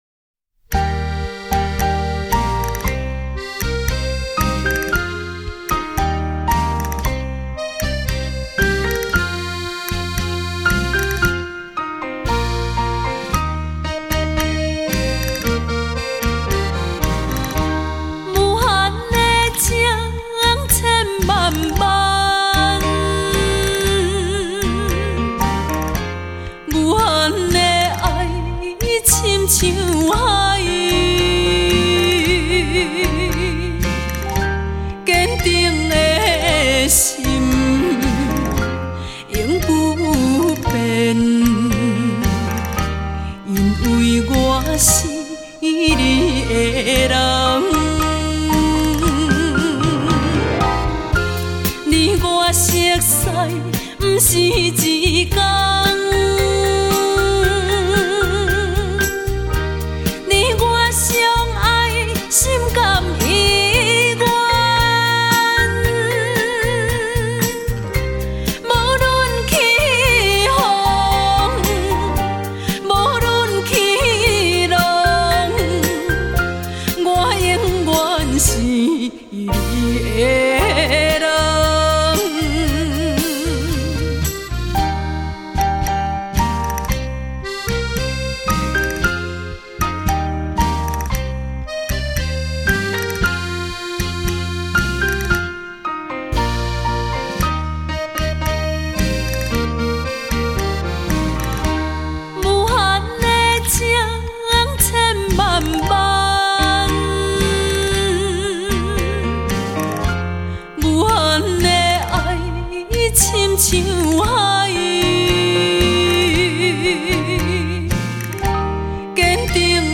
飞扬的音符 清新的风格